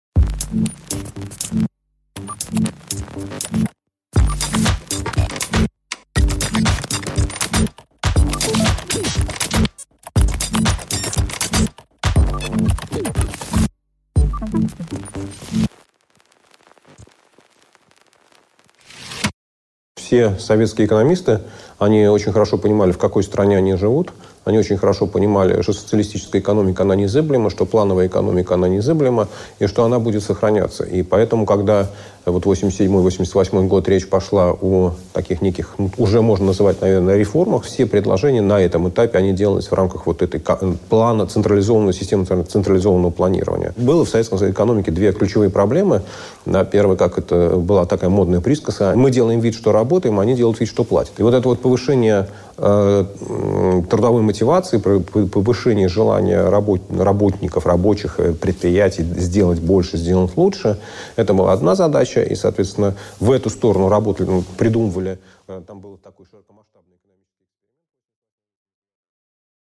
Аудиокнига Экономическая политика перестройки | Библиотека аудиокниг